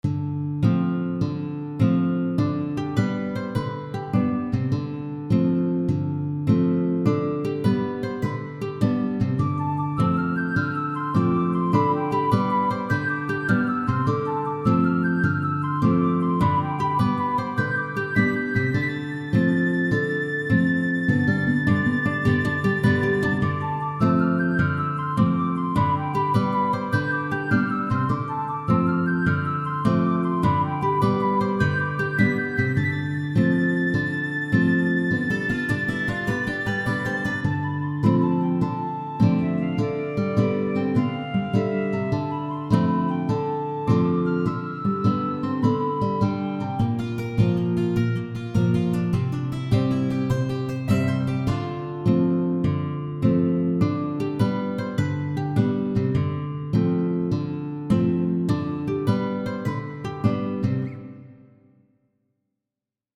A ancient style music.